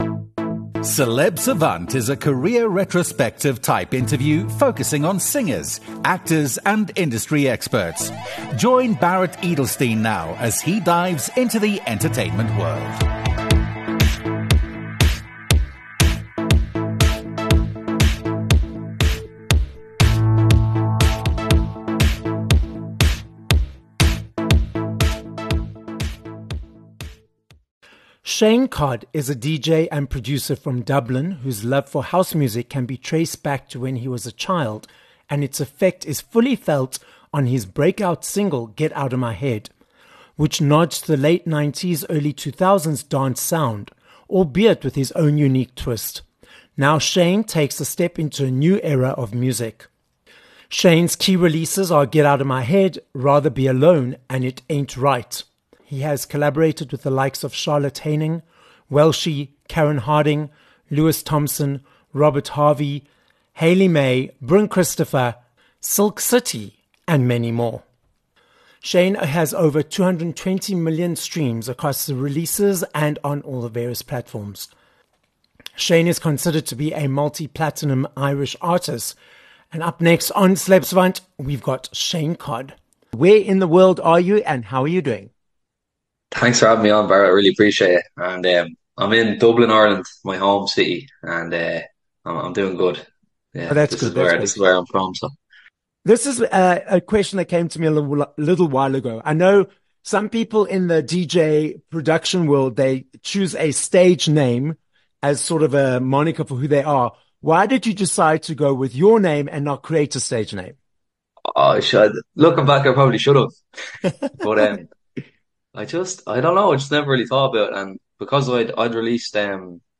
20 Aug Interview with Shane Codd
We head to Ireland on this episode of Celeb Savant, as we are joined by DJ and Producer, Shane Codd. Shane tells us how watching Tomorrowland and Conor McGregor motivated him to follow his successful journey in the music world - leading to top 10 singles on the charts and collaborating on remixes with Silk City, Karen Harding and more.